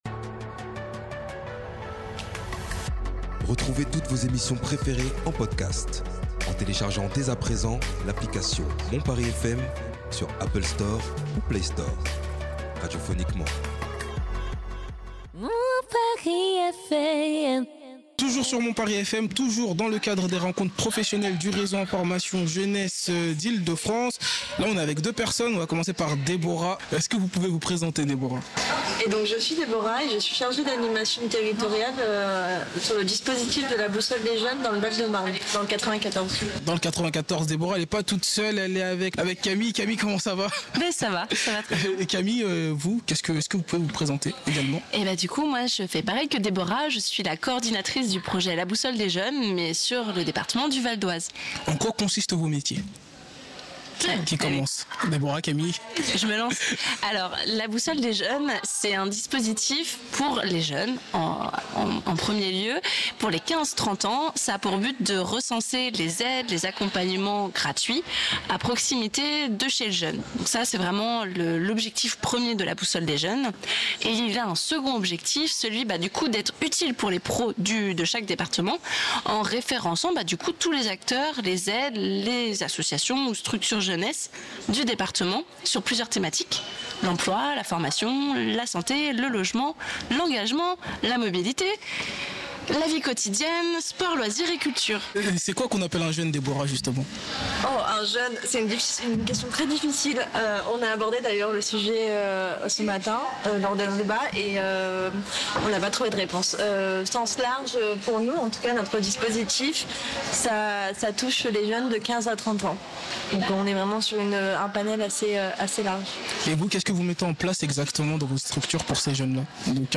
interviews avec différents informateurs jeunesse